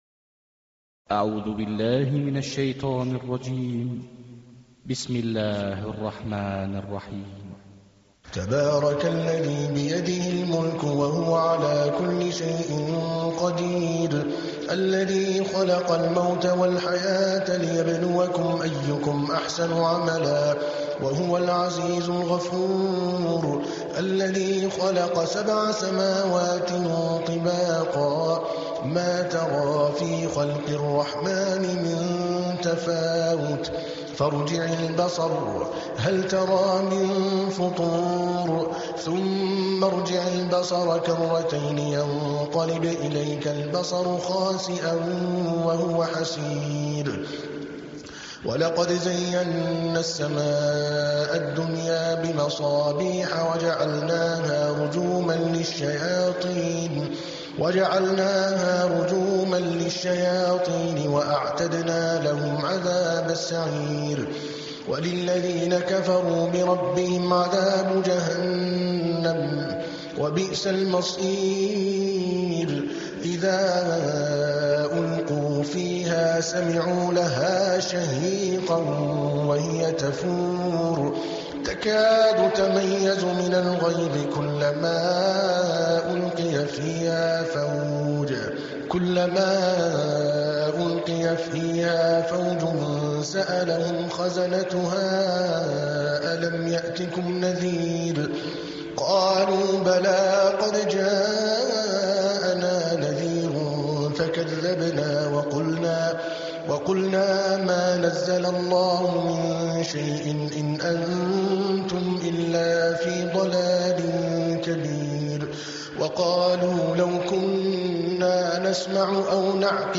67. Surah Al-Mulk سورة الملك Audio Quran Tarteel Recitation
Surah Repeating تكرار السورة Download Surah حمّل السورة Reciting Murattalah Audio for 67.